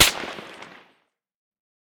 heav_crack_05.ogg